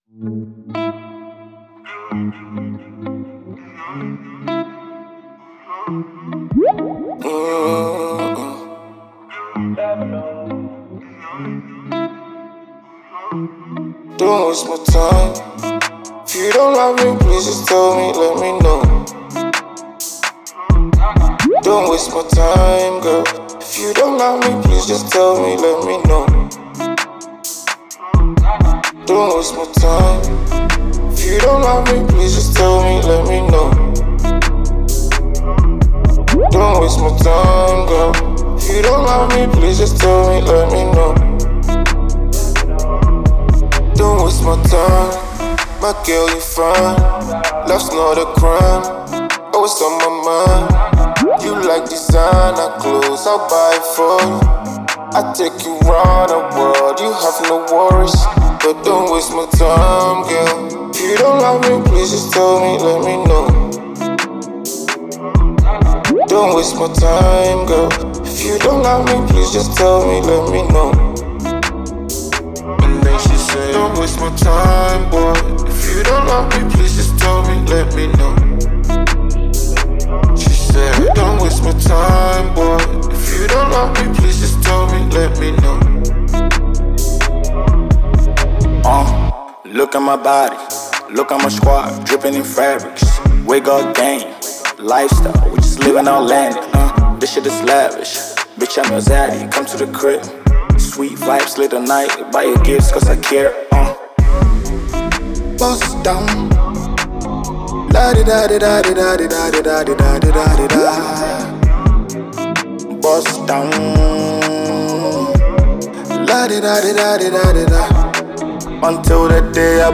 Ghanaian hip pop act